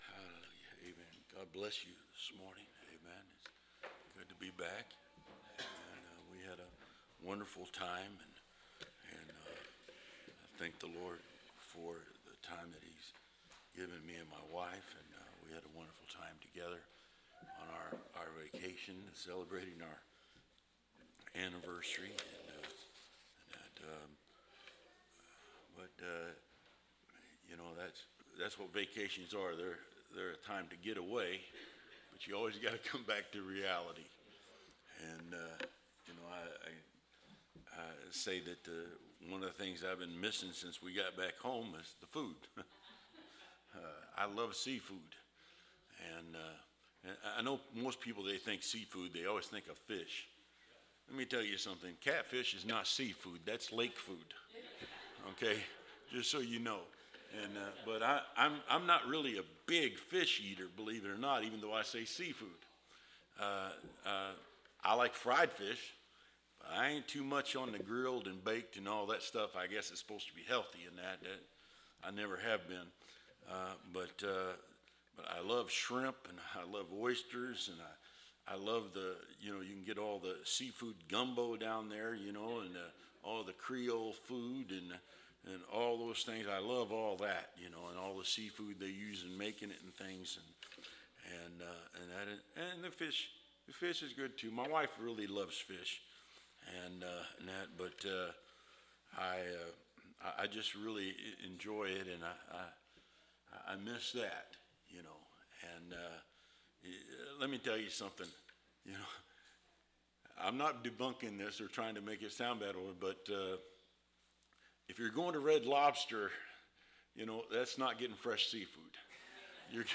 Preached November 17, 2024